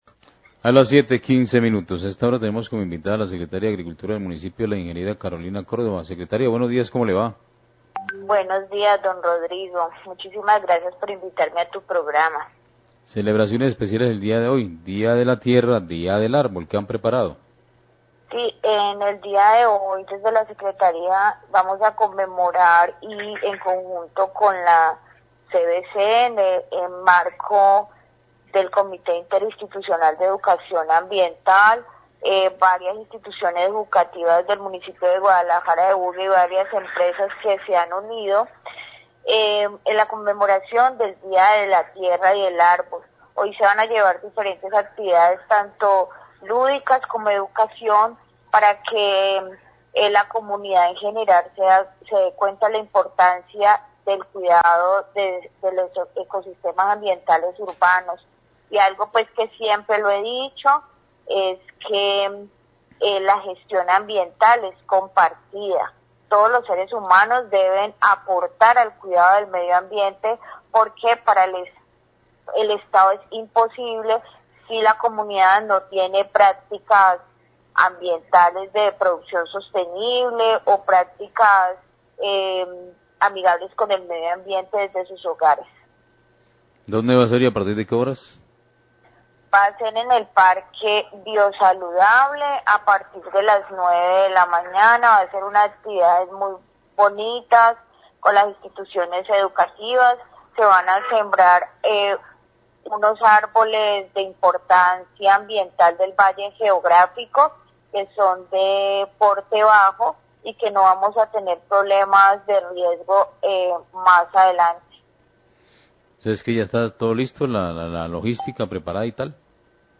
Radio
La Secretaria de Agricultura y Desarrollo Sostenible de Buga, ing. Carolina Córdoba, se refiere a la celebración del Día de la Tierra y del Arbol en el Parque Biosaludable El Vergel, que se realizó en conjunto con la CVC y empresas privadas. Una de las actividades es la siembra de árboles en compañía de la CVC.